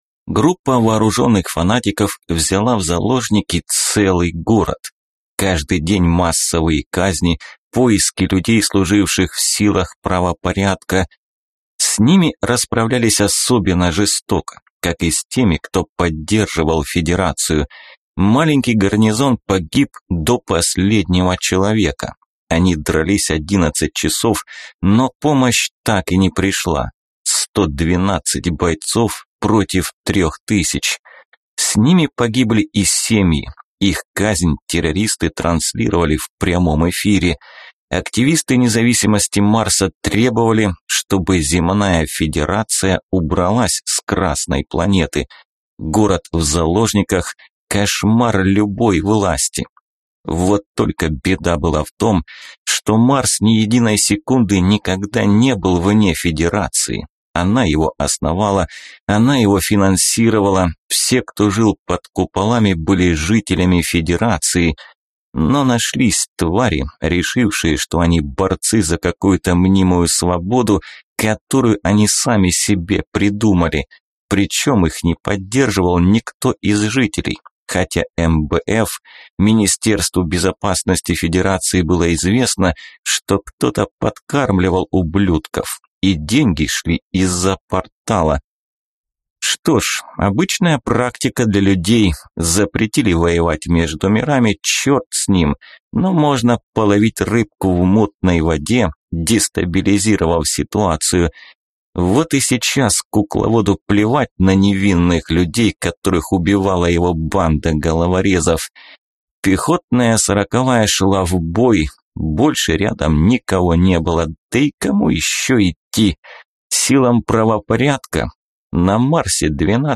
Аудиокнига По зову долга | Библиотека аудиокниг